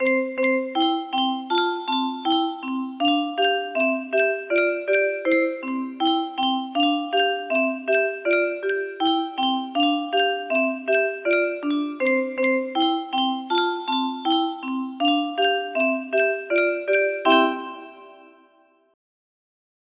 twinkle_twinkle music box melody